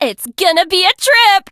janet_start_vo_06.ogg